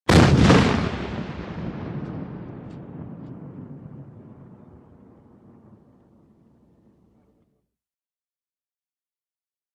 60 mm Mortar|Exterior | Sneak On The Lot
WEAPONS - CANNON 60 MM MORTAR: EXT: Single shot and echo.